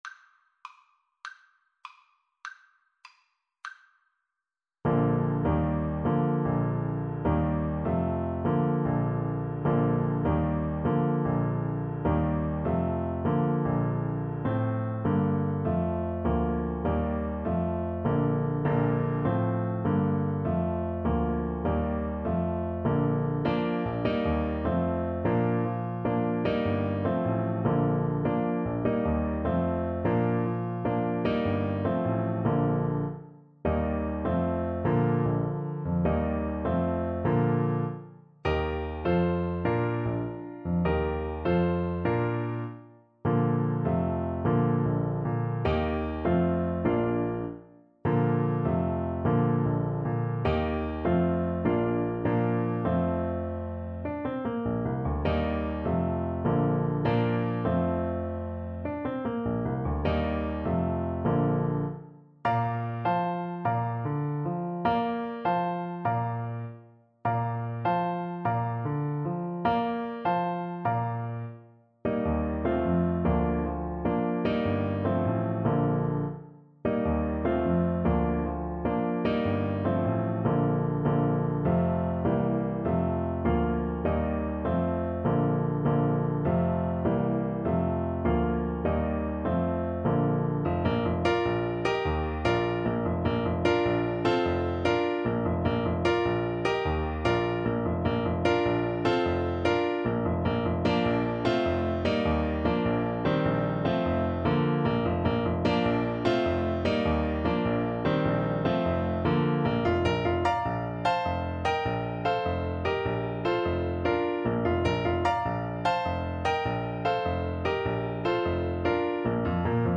6/8 (View more 6/8 Music)
Classical (View more Classical Clarinet Music)